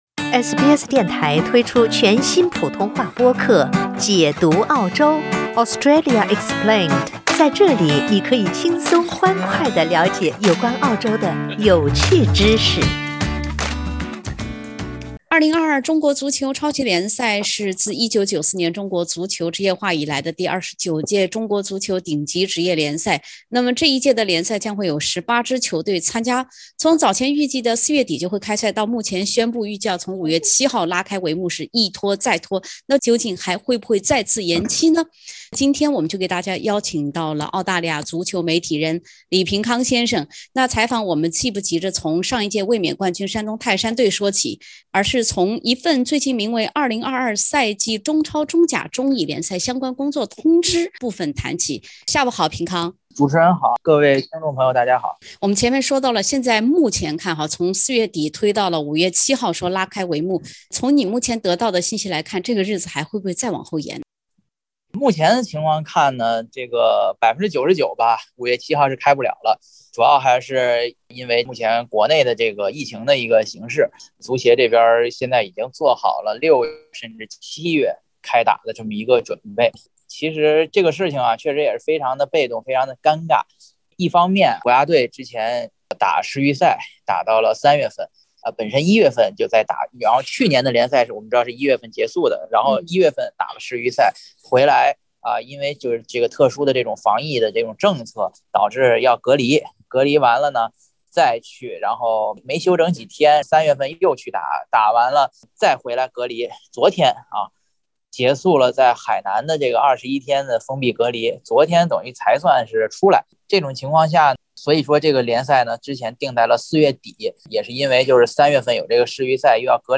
2022中超联赛一推再推，究竟何时才会开赛？（点击封面图片，收听完整采访）